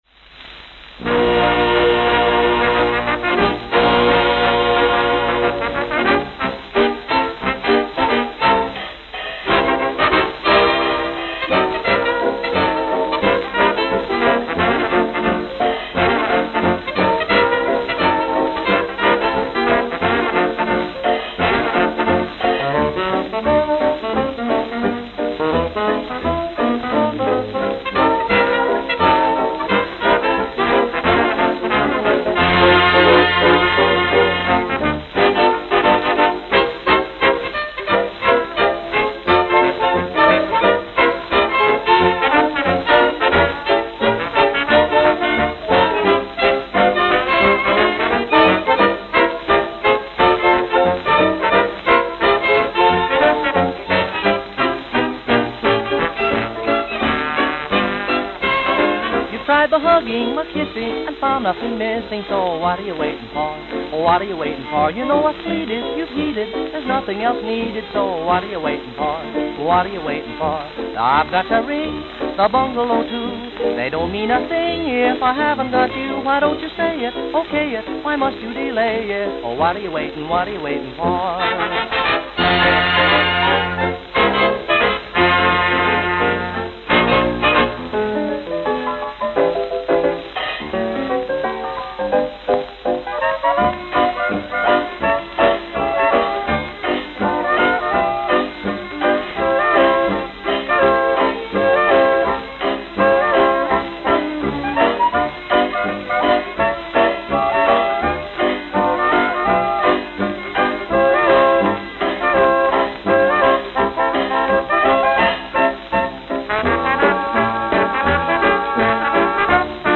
New York, New York. Liederkranz Hall New York, New York